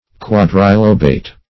Search Result for " quadrilobate" : The Collaborative International Dictionary of English v.0.48: Quadrilobate \Quad`ri*lo"bate\, Quadrilobed \Quad`ri*lobed\, a. [Quadri- + lobe: cf. F. quadrilob['e].] Having four lobes; as, a quadrilobate leaf.
quadrilobate.mp3